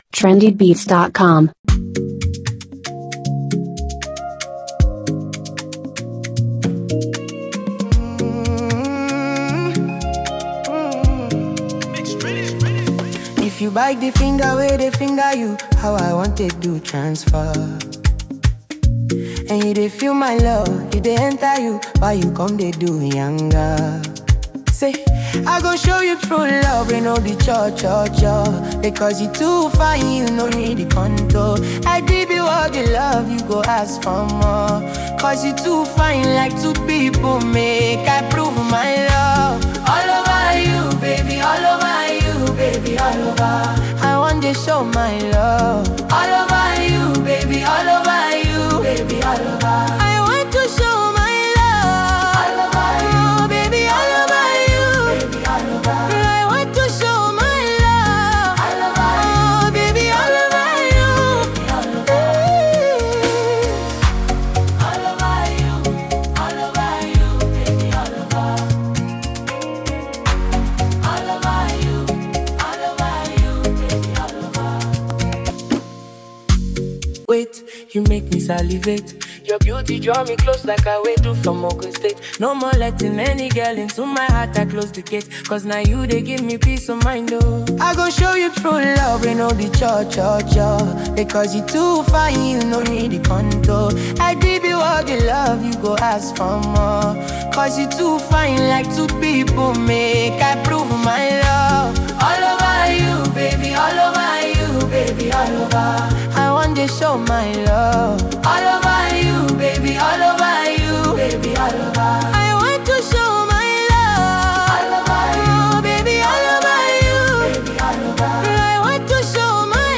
distinctive style and powerful vocals